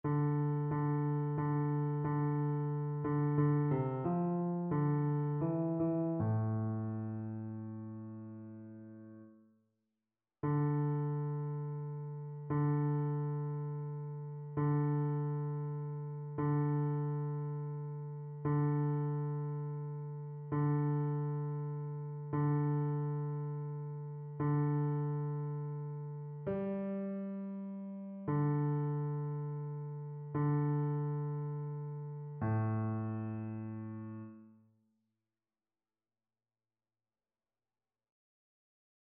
Chœur
Basse
annee-b-temps-pascal-3e-dimanche-psaume-4-basse.mp3